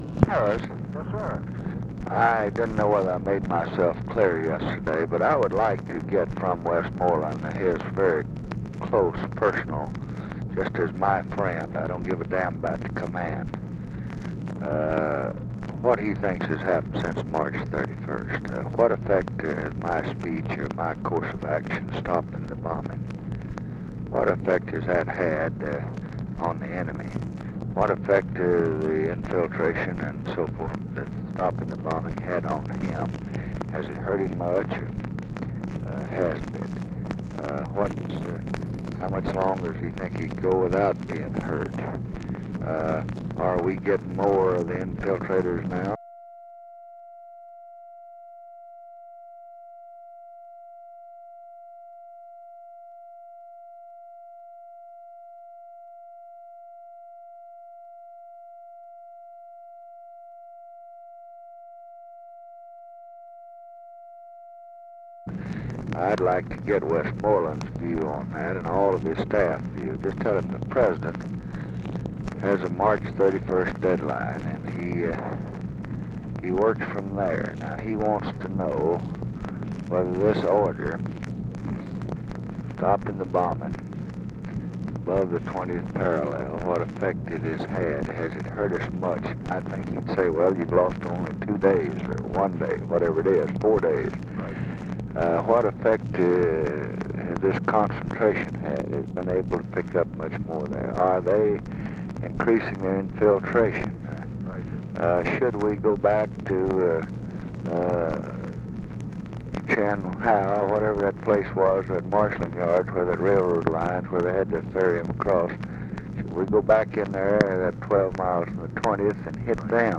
Conversation with EARLE WHEELER and OFFICE CONVERSATION, April 28, 1968
Secret White House Tapes